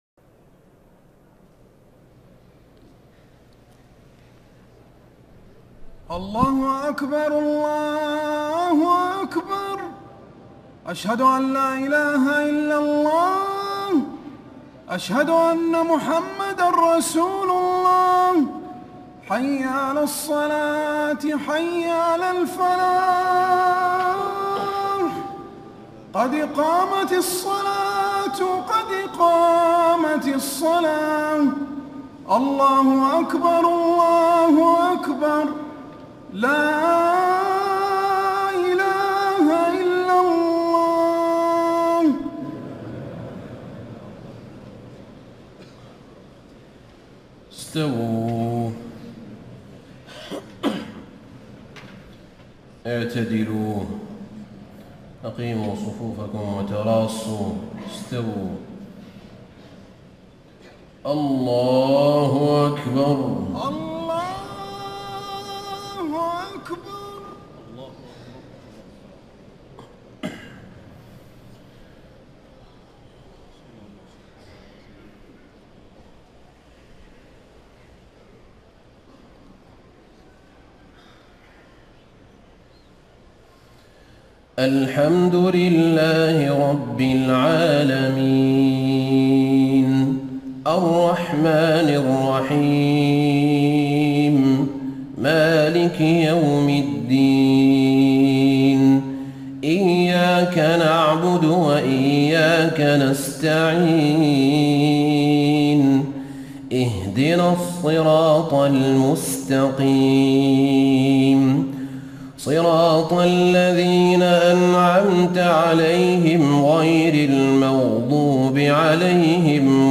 عشاء 15 شعبان ١٤٣٥ من سورة الغاشيه و الماعون > 1435 🕌 > الفروض - تلاوات الحرمين